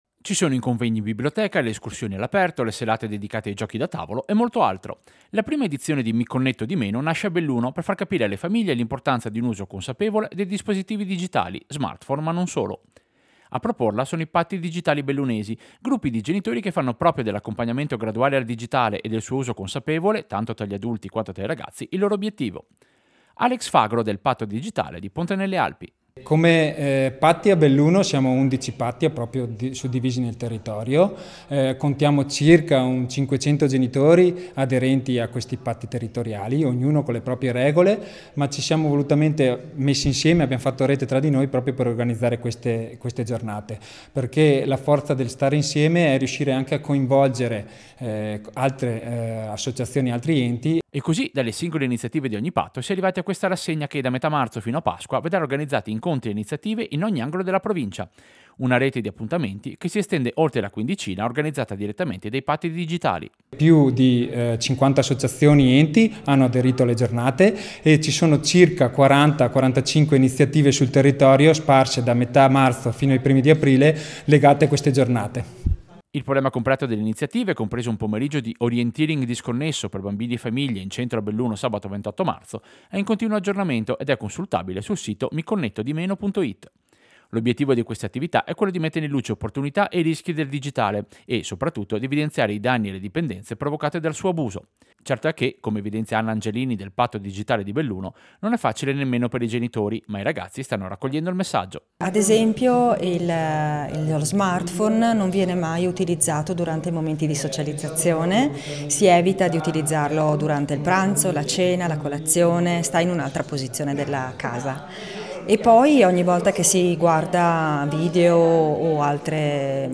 Servizio-Mi-connetto-di-meno.mp3